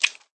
click_chink.ogg